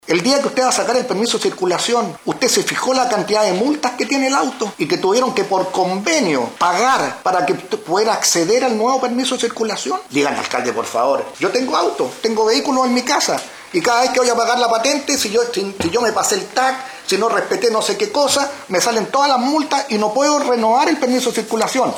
En una sesión extraordinaria del Concejo Municipal de Puchuncaví, región de Valparaíso, se abordaron las diversas irregularidades que rodean el accidente de tránsito protagonizado el pasado fin de semana por el alcalde de la comuna, Marcos Morales, quien a bordo de un vehículo municipal colisionó con otro automóvil en Quintero.
Por su parte, Agustín Valencia, concejal de la Democracia Cristiana, criticó la gran cantidad de multas que registraba el vehículo municipal involucrado en el accidente.